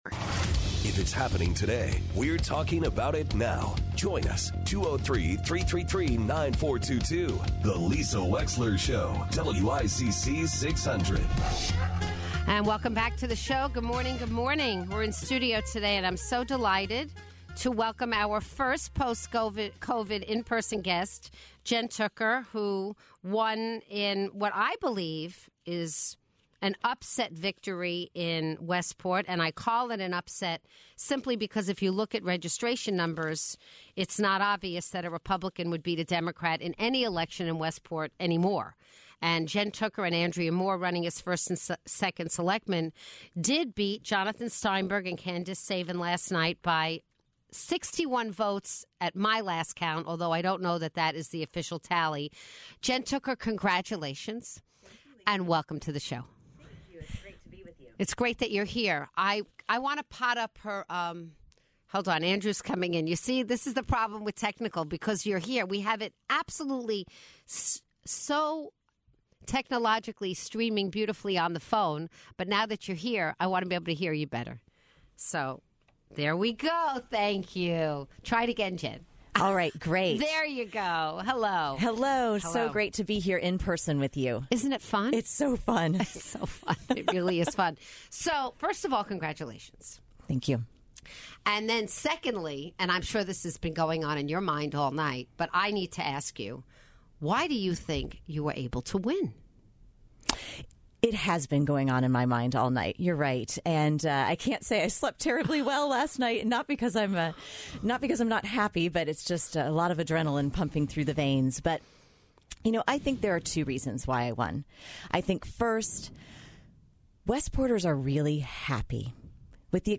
Newly elected First Selectman Jen Tooker in studio discussing the race for First Selectman and the future of Westport.